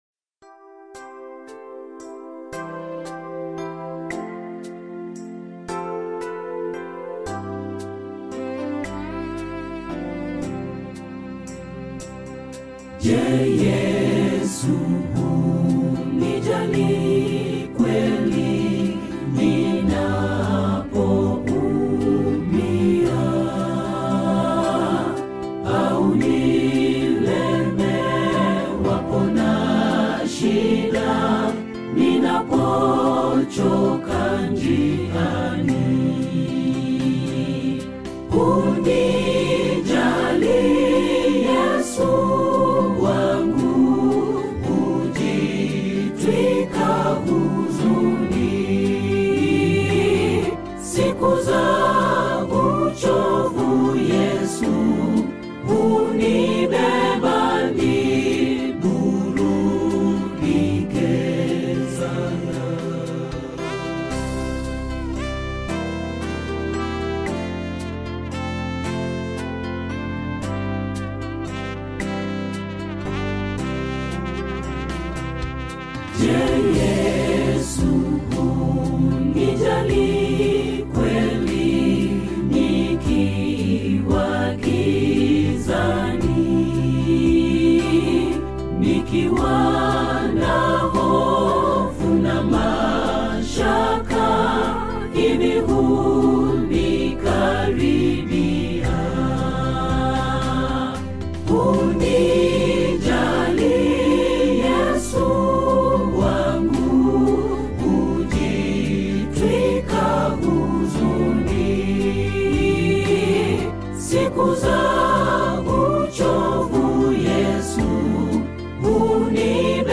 Swahili SDA Hymns